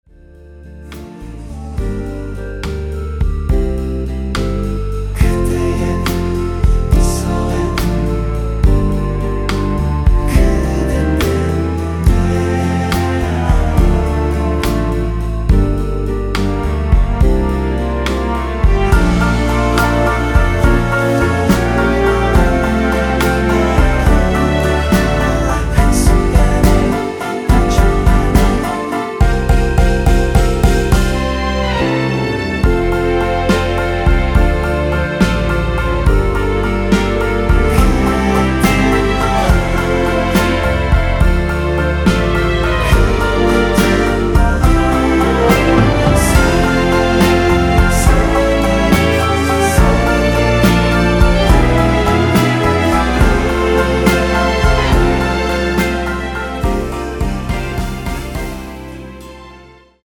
원키에서(-1)내린 멜로디와 코러스 포함된 MR입니다.(미리듣기 확인)
◈ 곡명 옆 (-1)은 반음 내림, (+1)은 반음 올림 입니다.
앞부분30초, 뒷부분30초씩 편집해서 올려 드리고 있습니다.